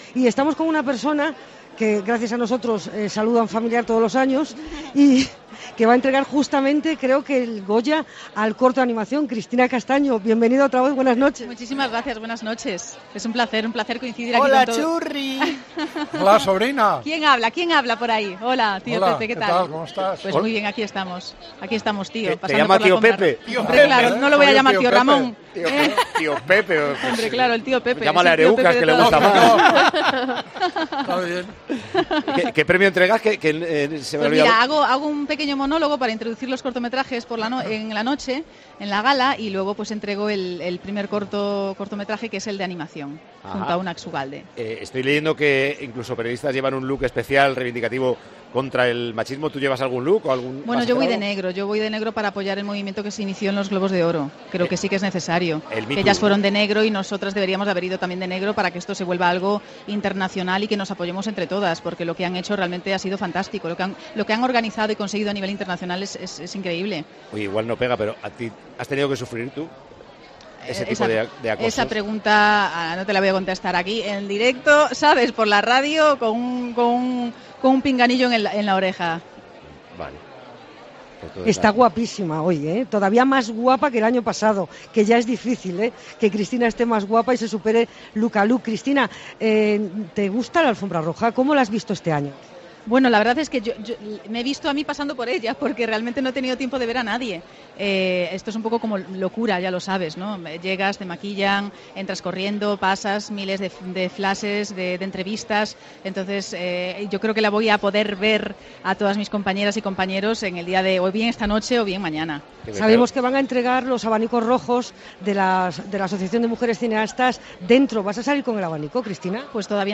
La actriz Cristina Castaño, que ha entregado este sábado, el Goya al corto de animaciónjunto a Unax Ugalde, ha saludado a su 'Tío Pepe', Pepe Domingo Castaño, en 'Tiempo de Juego', desde la alfombra roja.